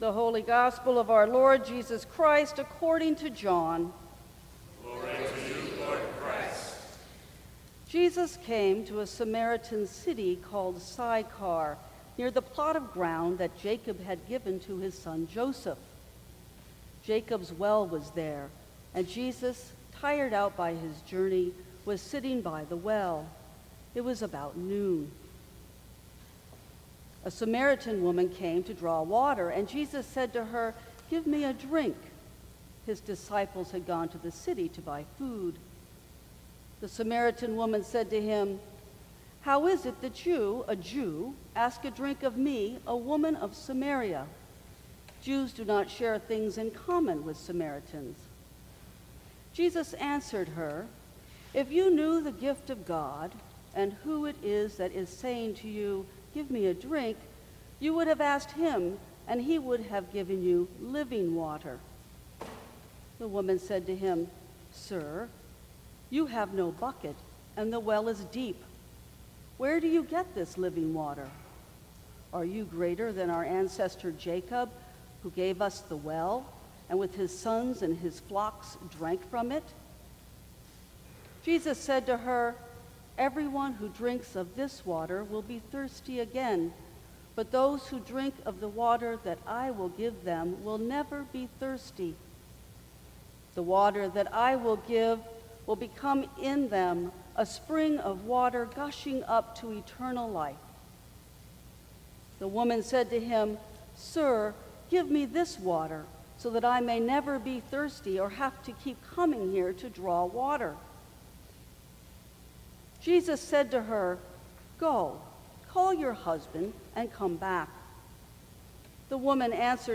Sermons from St. Cross Episcopal Church Defying Expectations Mar 19 2017 | 00:23:36 1x 00:00 / 00:23:36 Subscribe Share Apple Podcasts Spotify Overcast RSS Feed Share Link Embed